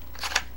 Weapon Attachment Disable.wav